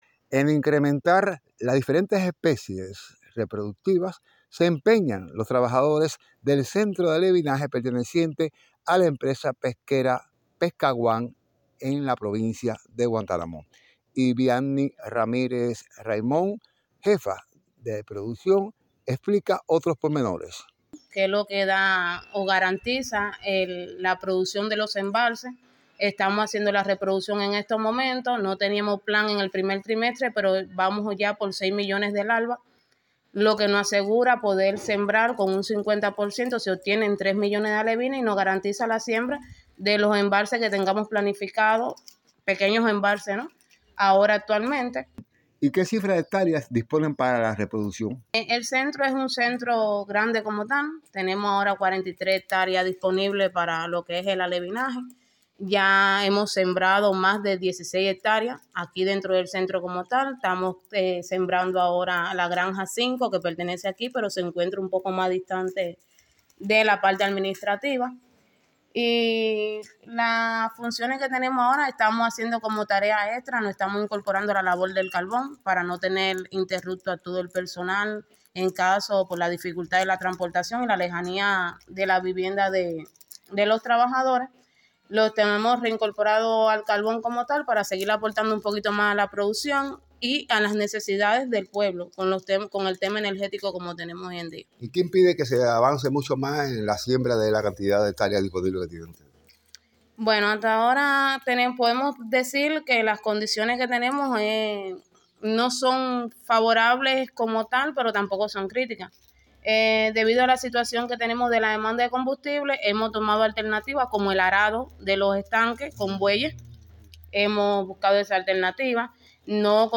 escuchemos el reporte del periodista